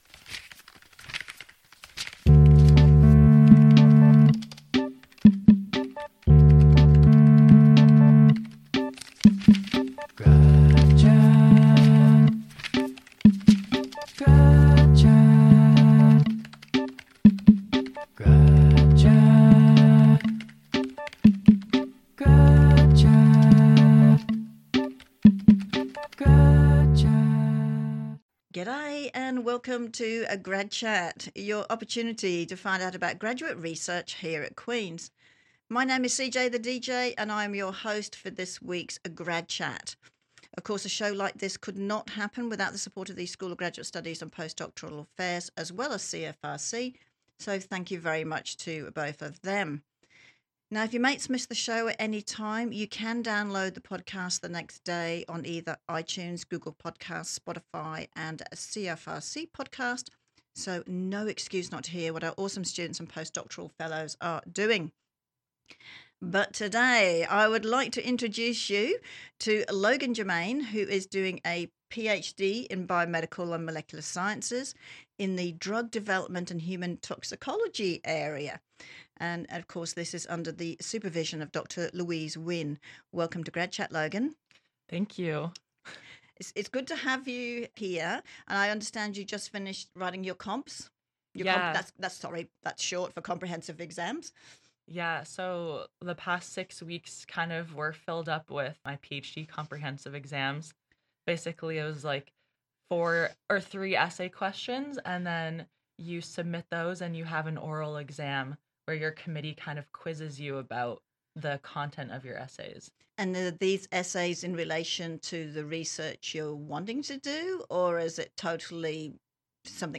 A 30 minute radio show featuring one to two graduate students each week. This is an opportunity for our grad students to showcase their research to the Queen’s and Kingston community and how it affects us. From time to time we will also interview a post-doc or an alum or interview grad students in relation to something topical for the day.